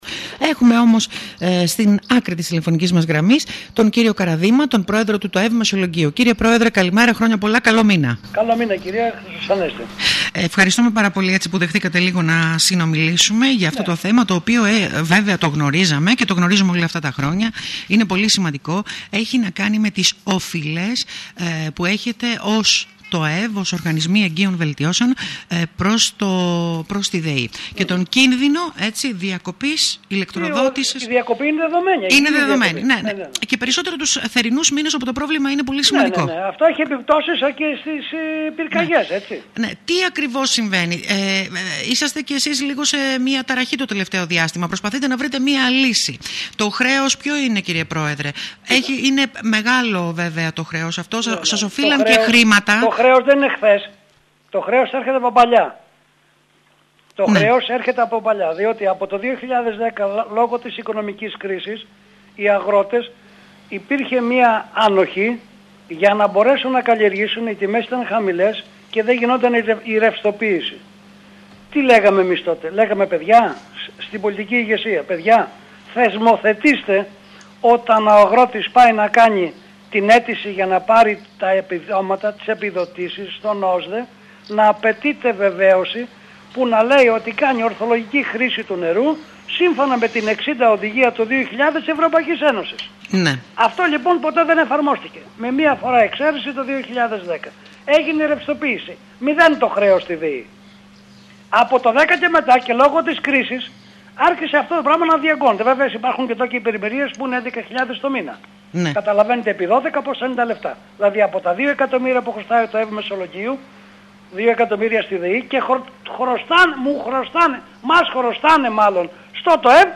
μιλώντας στον Ραδιοφωνικό Σταθμό της πόλης